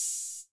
Closed Hats
pcp_openhihat01.wav